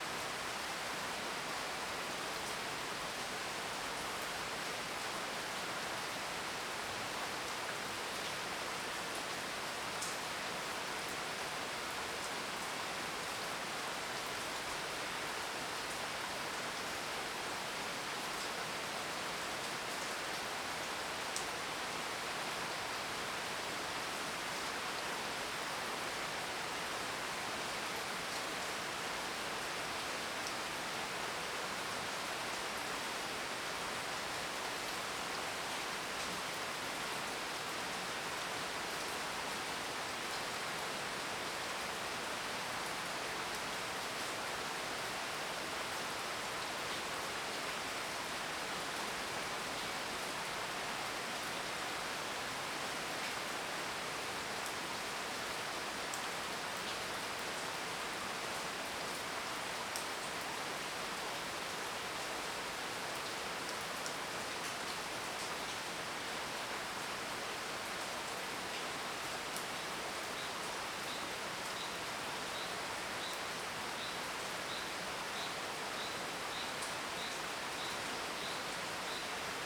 CSC-09-024-OL- Interior de casa com chuva.wav